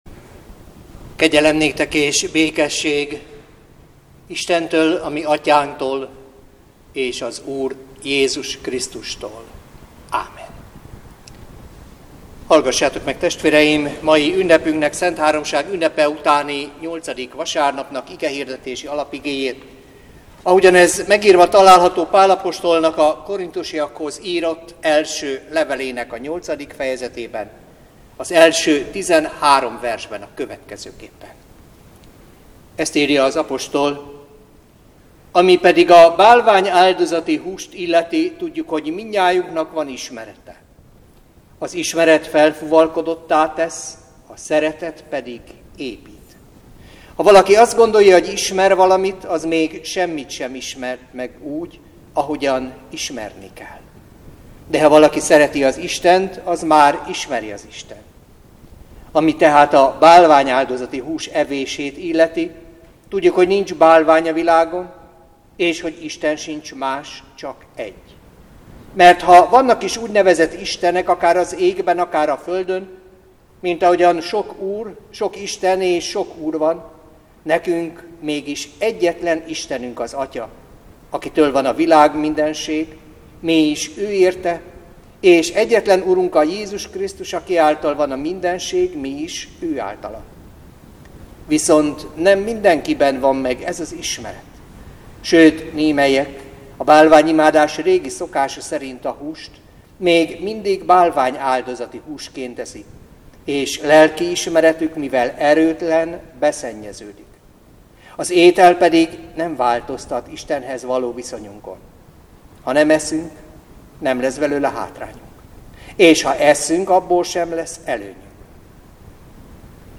Szentháromság ünnepe utáni nyolcadik vasárnap - Éljetek úgy mint a világosság gyermekei.
Igét hirdet